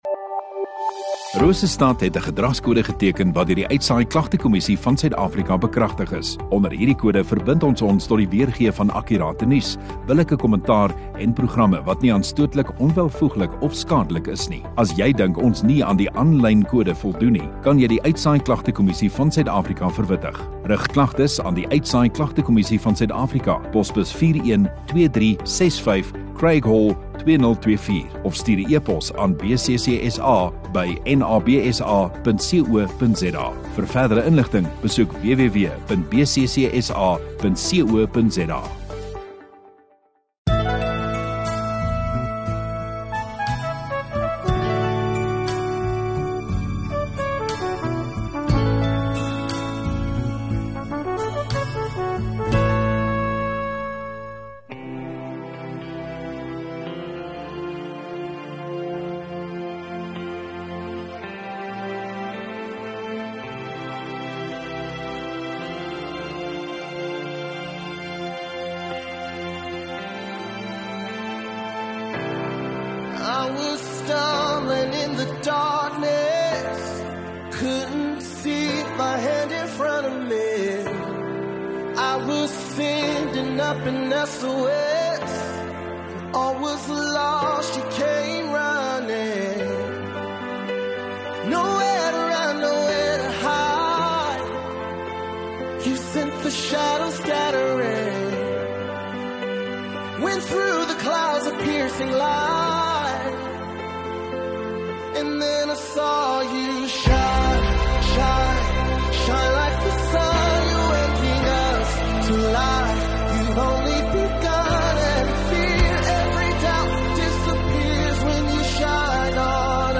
MultiMedia LIVE View Promo Continue Install Rosestad Godsdiens 3 Aug Sondagoggend Erediens